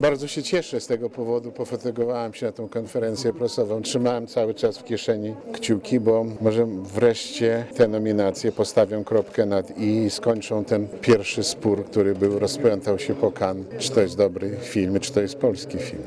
Obecny na uroczystości odczytania nominacji producent "Pianisty" Lew Rywin powiedział, że cały czas trzymał kciuki za ten film.